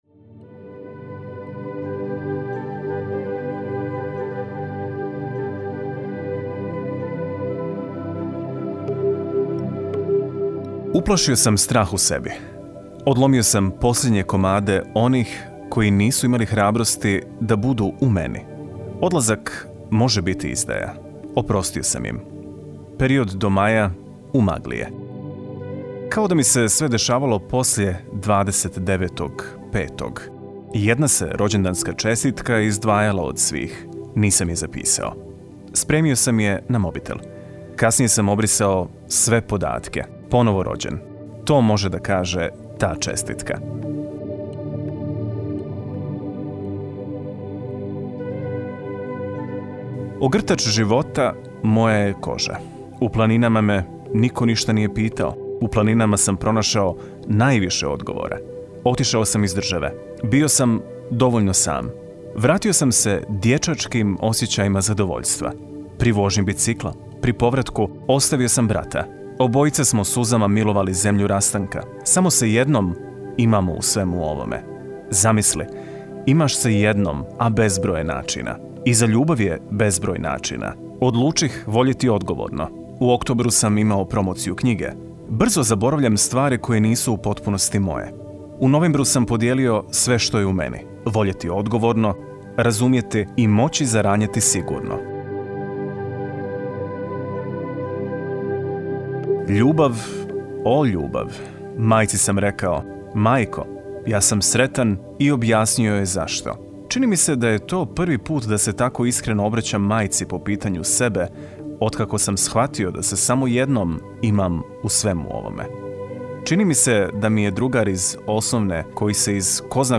Prijatelji OP-a pisali su o događajima po kojima će pamtiti 2019.-u godinu, a priče su interpretirali studenti i studentice IV godine Odsjeka za glumu Akademije scenskih umjetnosti u Sarajevu.